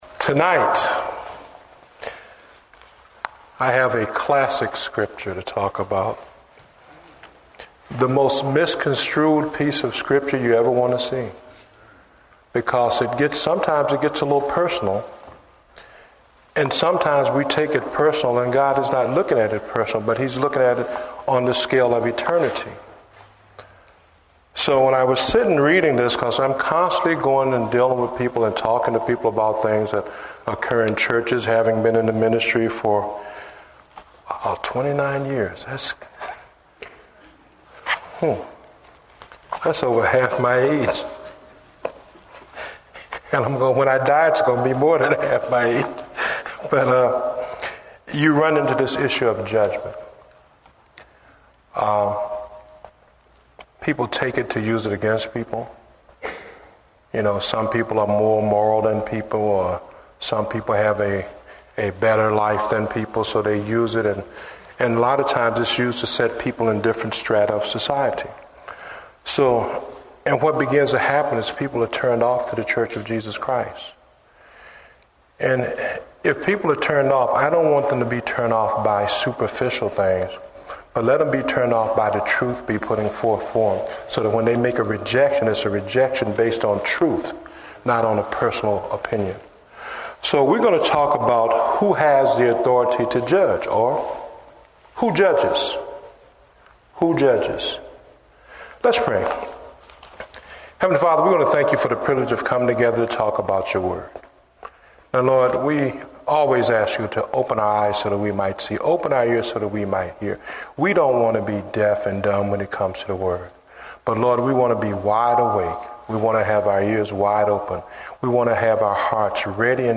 Date: January 11, 2009 (Evening Service)